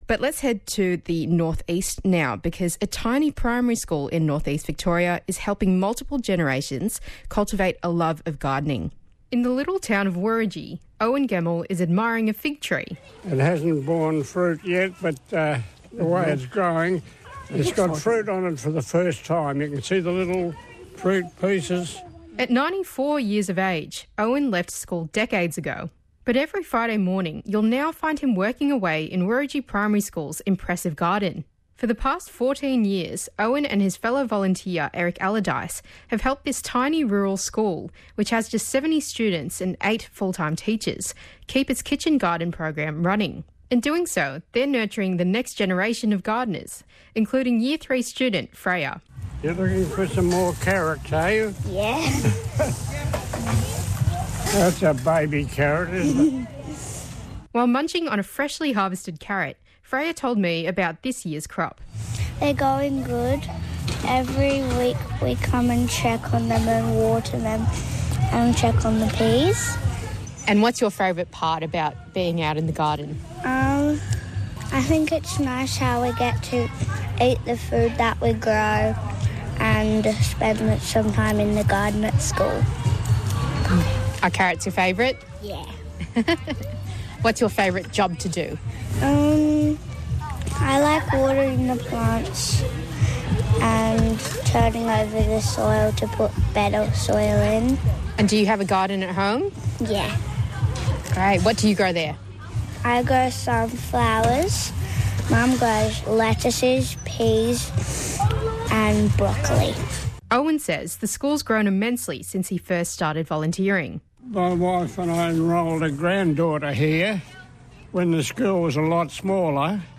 Hear firsthand from our valued greenthumbs volunteers.
abc-greenthumbs-interview.mp3